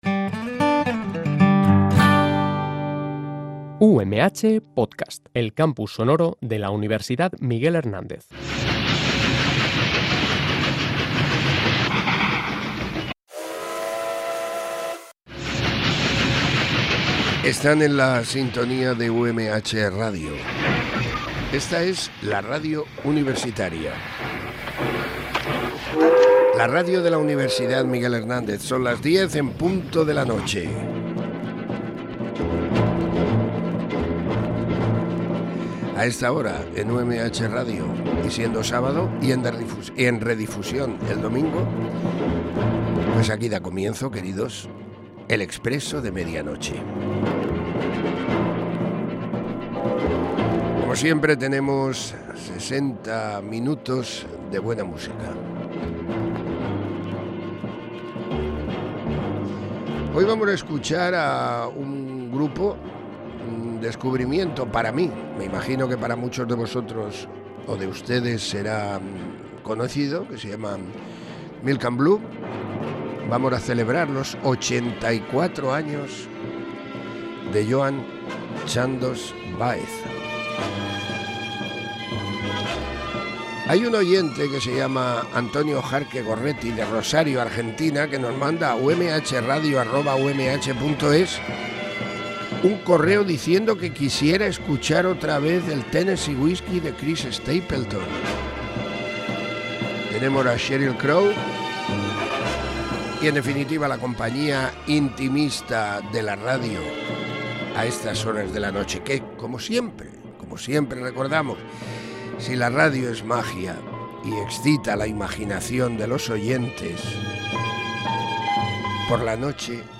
banjo
en dirccto